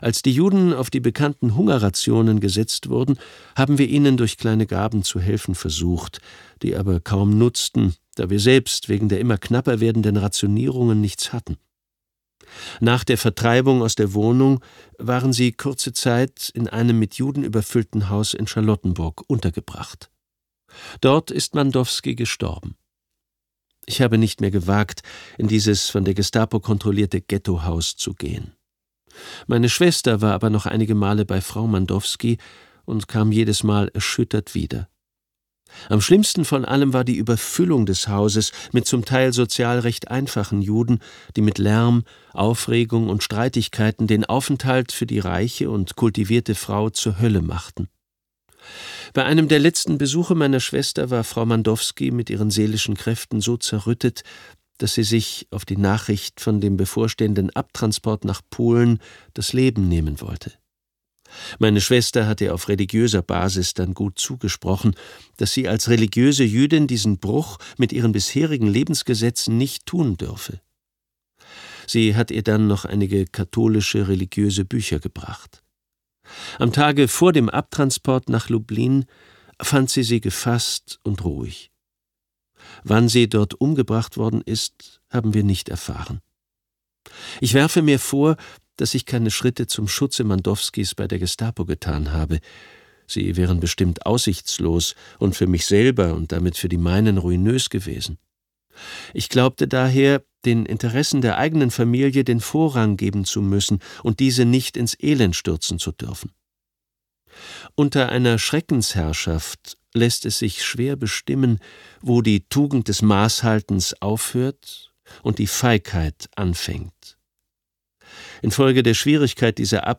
Mit seiner angenehm zurückhaltenden, aber fein-nuancierten Sprechkunst nimmt er jeden Hörer mit.